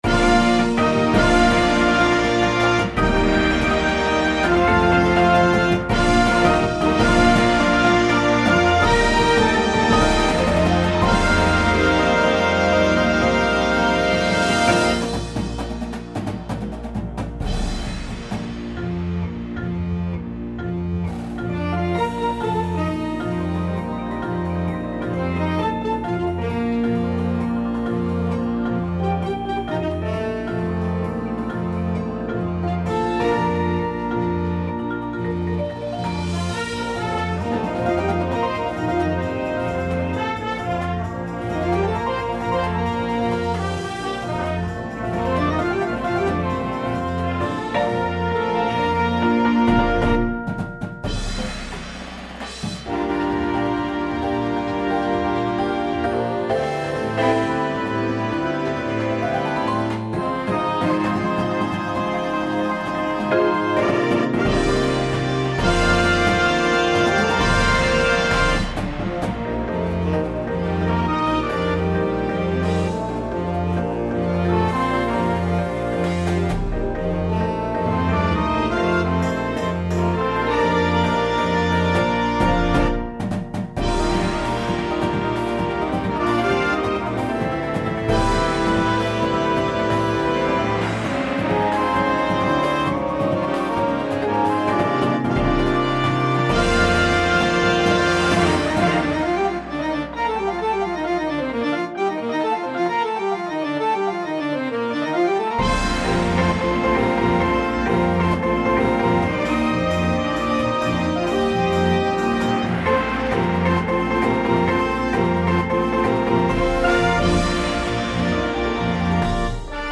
• Flute
• Clarinet 1, 2
• Alto Sax 1, 2
• Trumpet 1
• Horn in F
• Trombone 1, 2
• Tuba
• Snare Drum
• Synthesizer – Two parts
• Marimba – Two parts